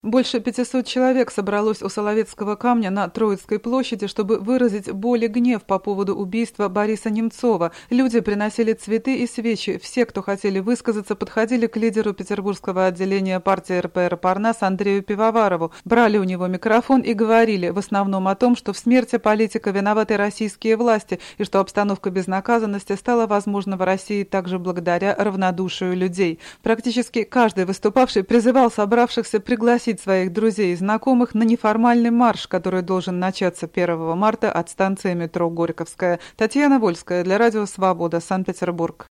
Из Петербурга передает корреспондент Радио Свобода